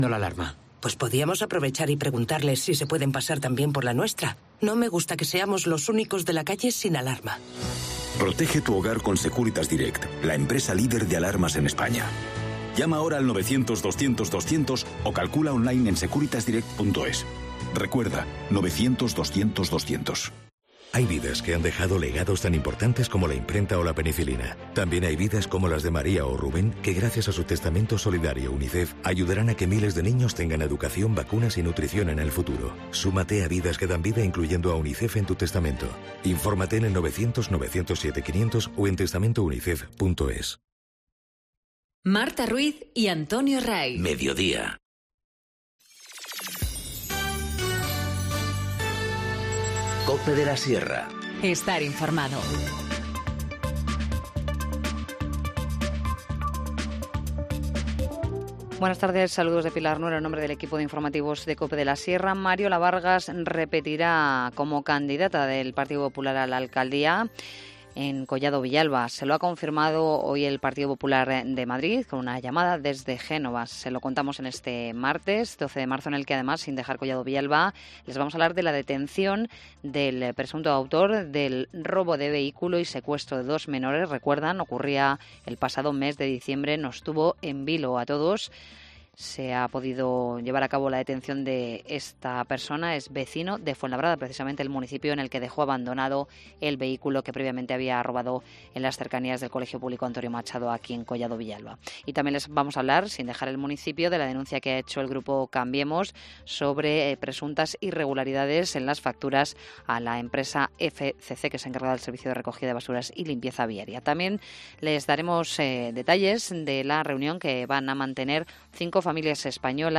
Informativo Mediodía 12 marzo 14:20h